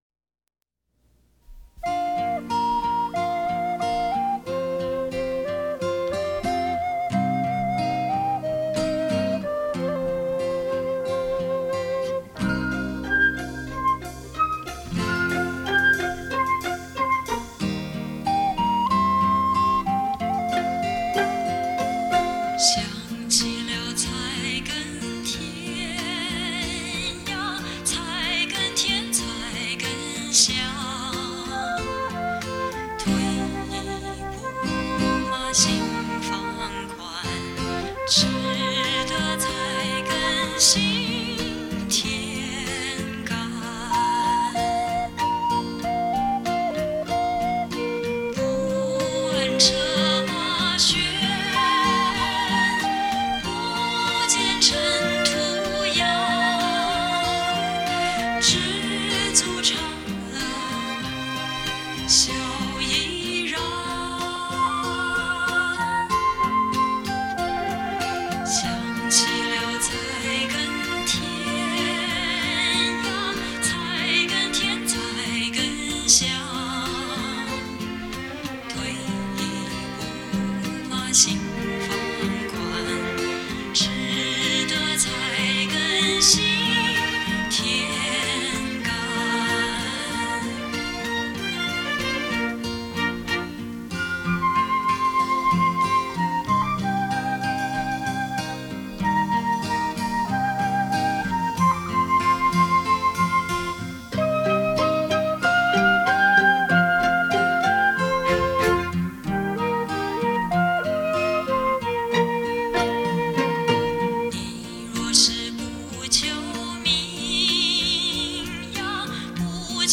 她的嗓音拥有无与伦比的致命吸引力，
清新中回荡着一种温柔，
一个美丽的女人用心绪演绎动人的情歌，